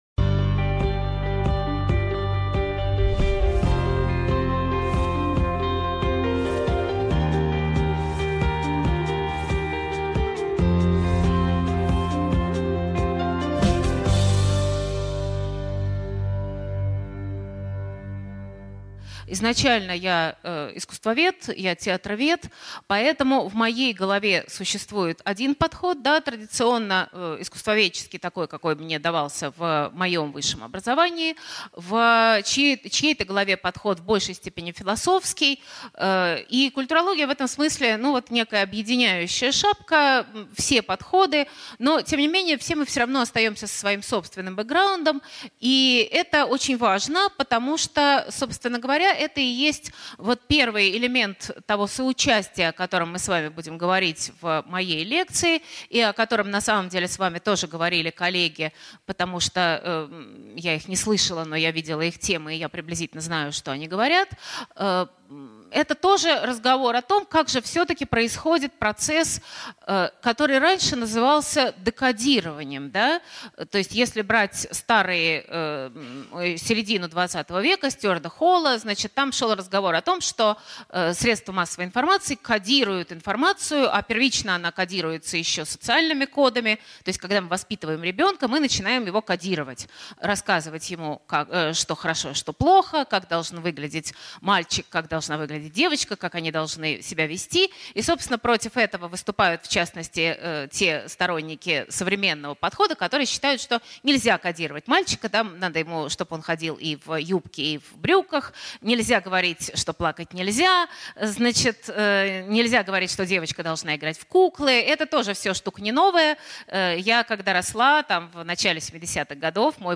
Аудиокнига Драма соучастия. Как театр, кинематограф, музей и университет выживают в трансмедийном мире | Библиотека аудиокниг